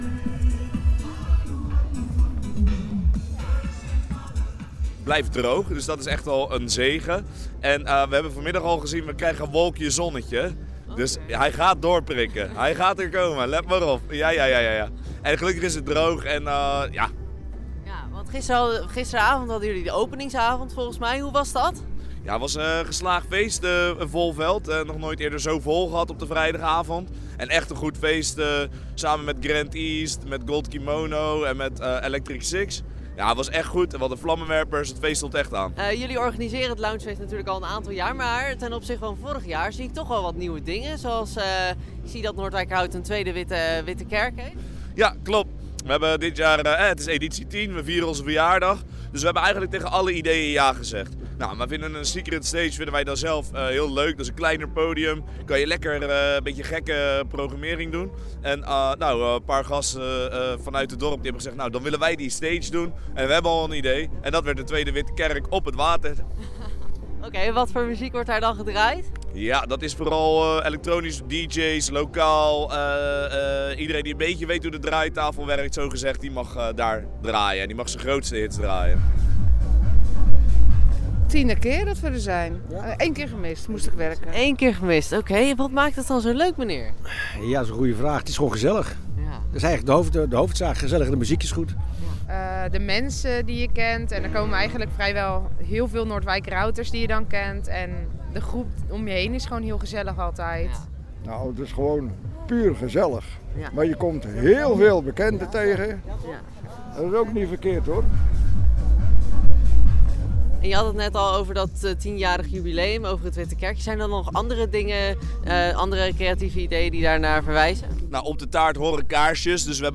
was aanwezig op het festival